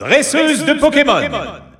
Announcer pronouncing female Pokémon Trainer in French.
Pokémon_Trainer_F_French_Announcer_SSBU.wav